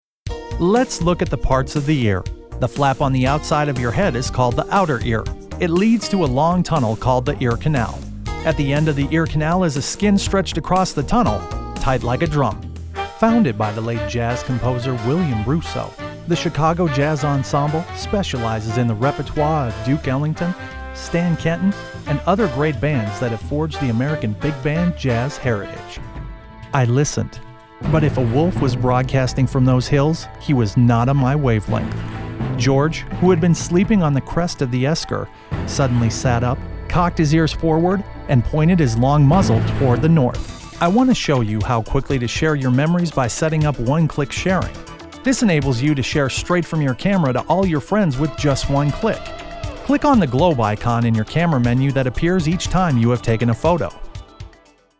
Studio equipment includes a Rode NT1-A condenser microphone and Pro Tools software.
Kein Dialekt
Sprechprobe: eLearning (Muttersprache):